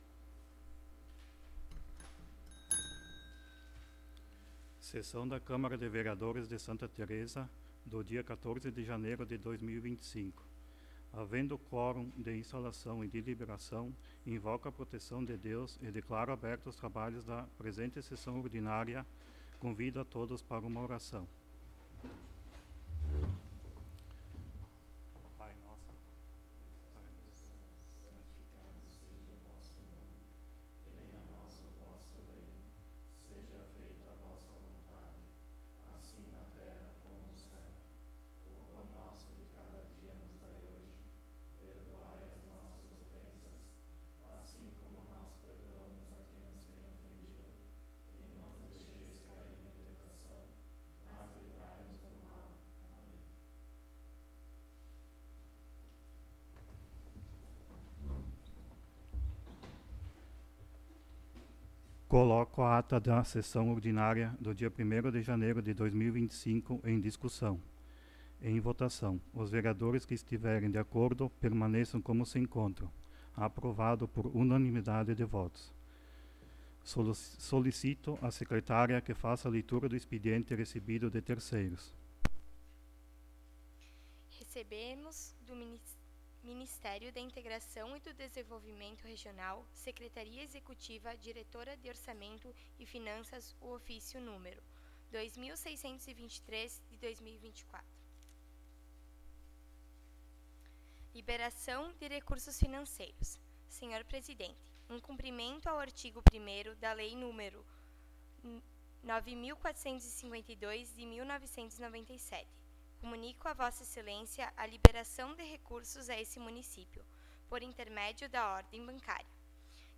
01° Sessão Ordinária de 2025
Áudio da Sessão